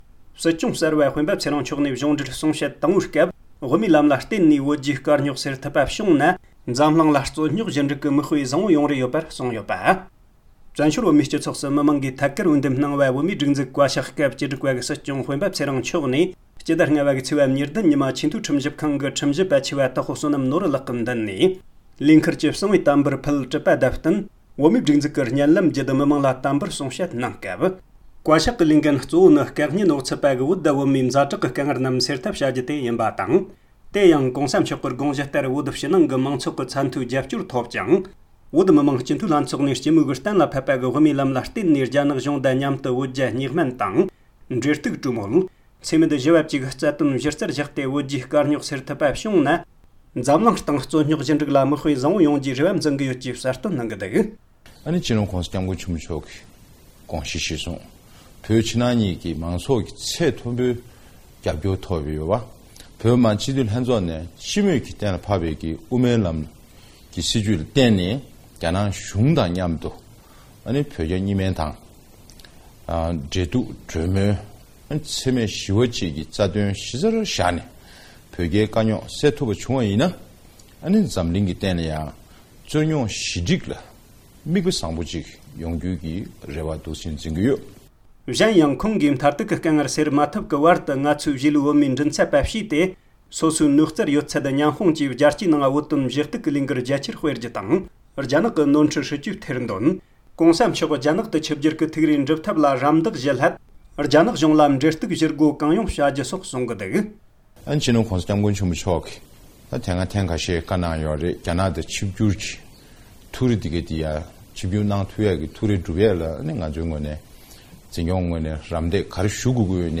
སྲིད་སྐྱོང་གསར་པ་སྤེན་པ་ཚེ་རིང་མཆོག་གིས་གཞུང་འབྲེལ་གསུང་བཤད་དང་པོ།
སྒྲ་ལྡན་གསར་འགྱུར། སྒྲ་ཕབ་ལེན།